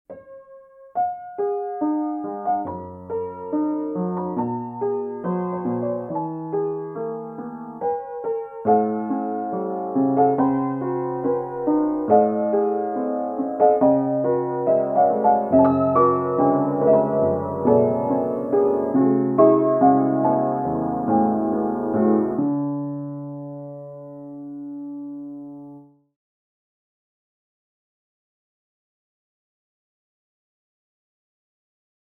Practice on Scriabin style